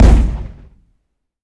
weapon_cannon_shot_04.wav